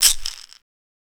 Shaker [1].wav